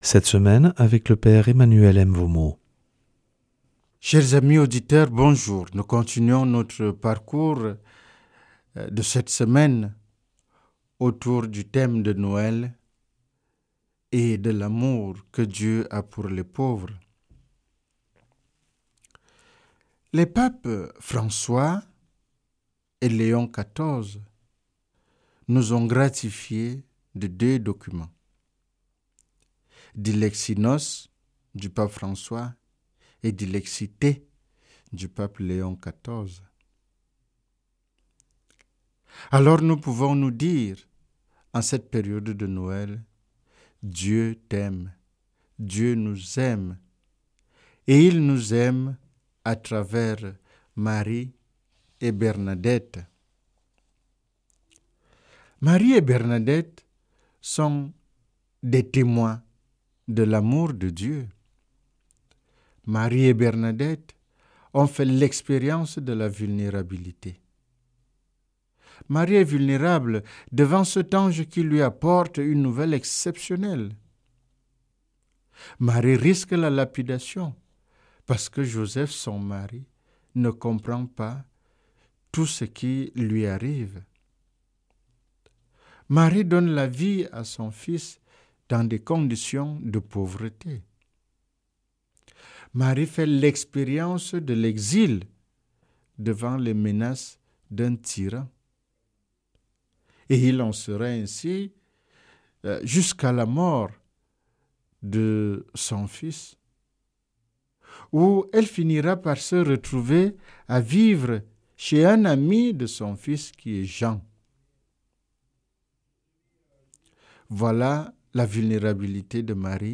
jeudi 25 décembre 2025 Enseignement Marial Durée 10 min